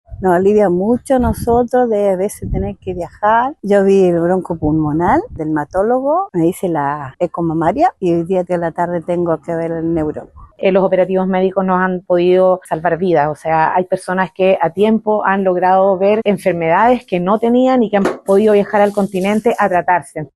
cu-operativo-medico-juan-fernandez-mix-pacientes.mp3